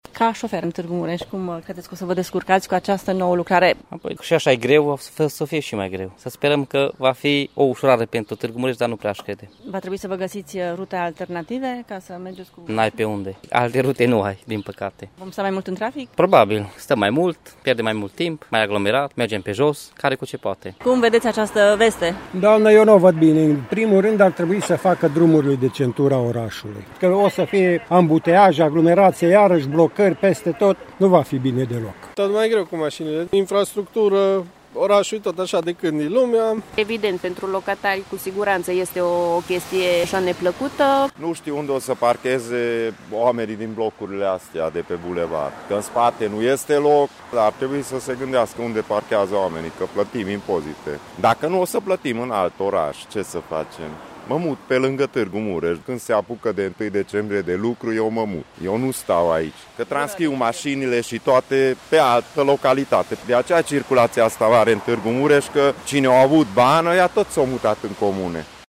Târgumureșenii din zona cartierului Tudor nu sunt foarte încrezători în acest proiect și se gândesc la mijloace alternative de locuit: